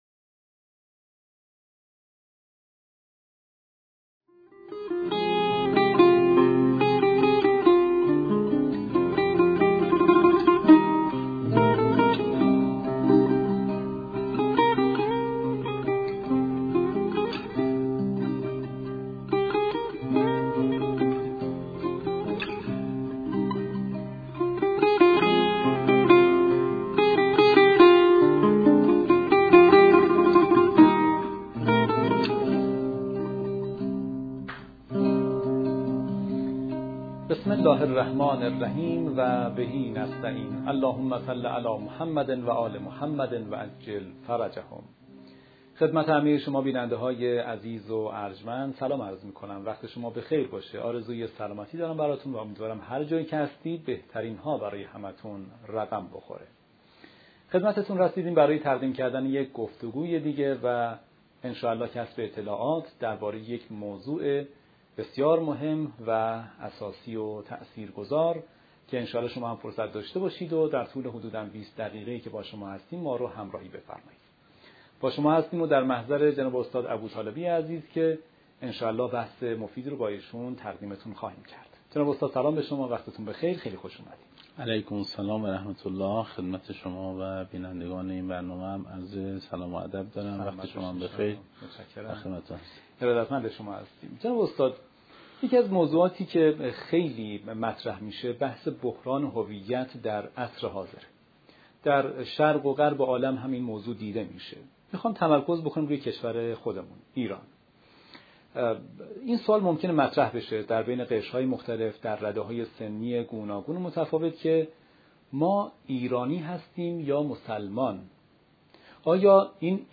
این گفتگو به بررسی رابطه هویت ایرانی و اسلامی می‌پردازد و به این پرسش اساسی پاسخ می‌دهد که آیا ایرانی بودن در خدمت اسلام است یا اسلام در خدمت هویت ایرانی.